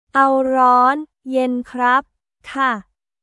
アオ ローン / イェン クラップ/カ